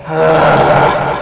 Growl Sound Effect
Download a high-quality growl sound effect.
growl-4.mp3